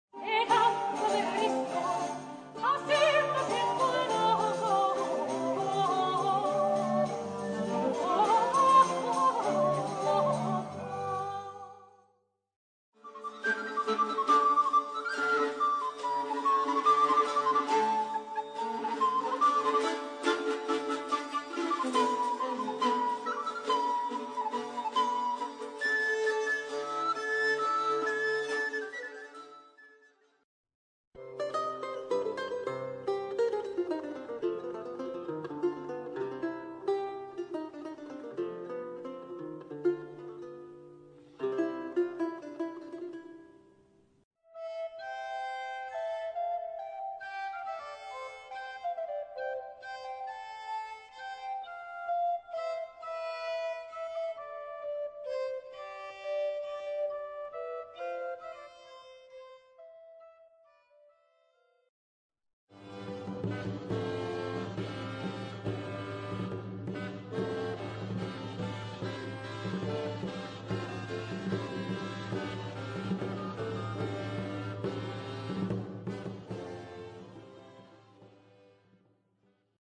L'audio propone cinque brevi frammenti di musica medievale.
musica_trovadorica.mp3